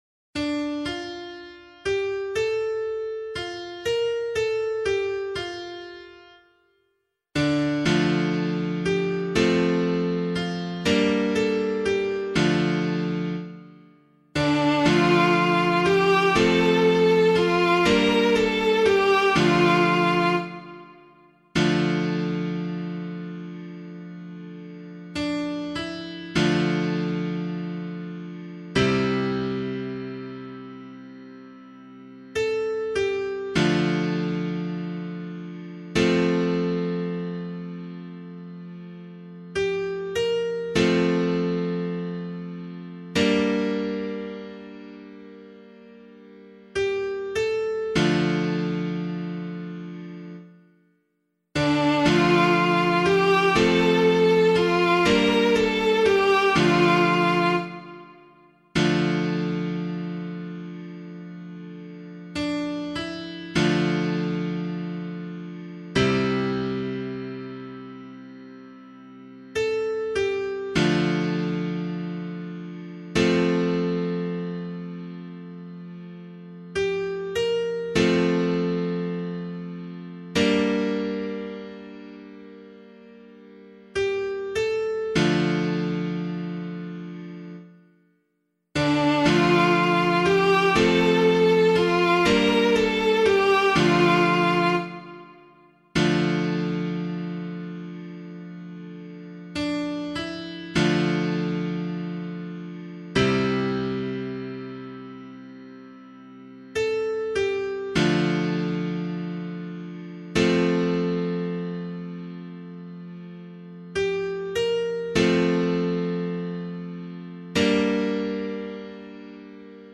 011 Baptism of the Lord Psalm A [LiturgyShare 1 - Oz] - piano.mp3